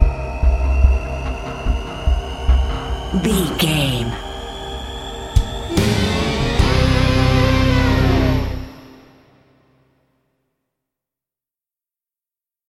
Fast paced
In-crescendo
Thriller
Aeolian/Minor
synthesiser